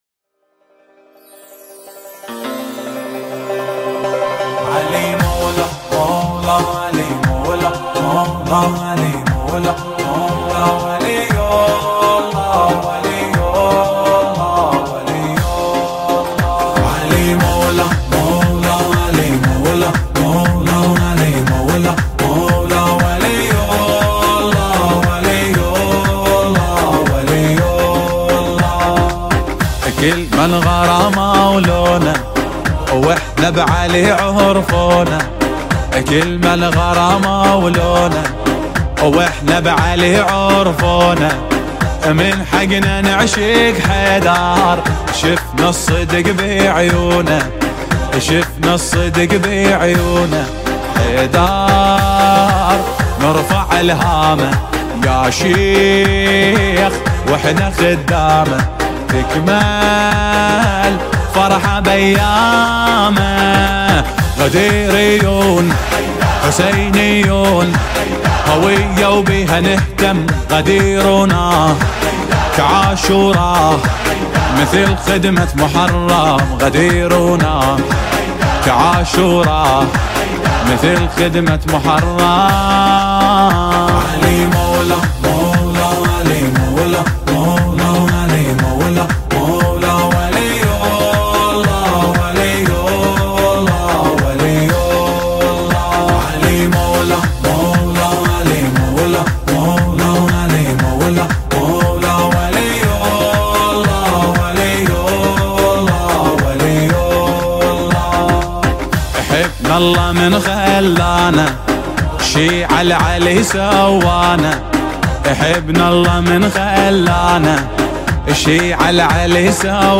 نماهنگ عربی زیبا و دلنشین